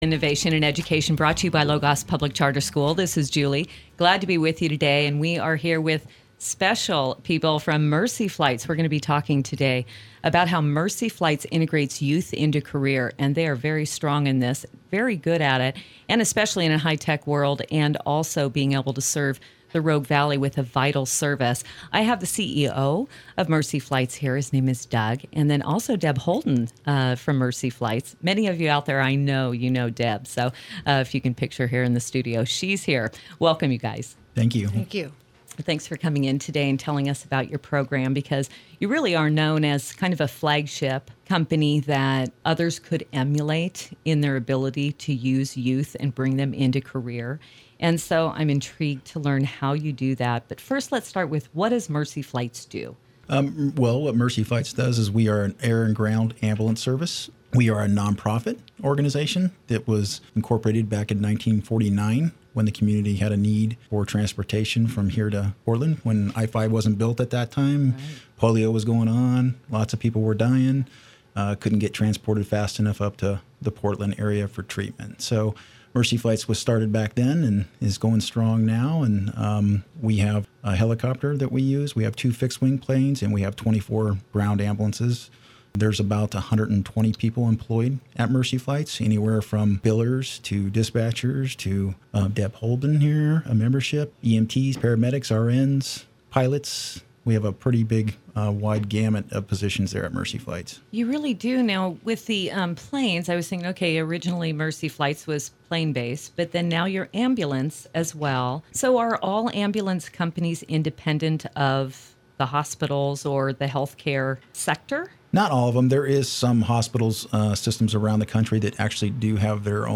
Listen to our CEO chat about our community programs in a recent radio broadcast.